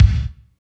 30.08 KICK.wav